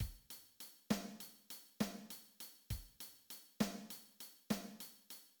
复三拍子鼓点：三拍，每拍三等分
（复三拍子）有三拍，每拍三等分。即第一个八分音符主重拍，第四、七两个八分音符次重拍。
Compound_triple_drum_pattern.mid.mp3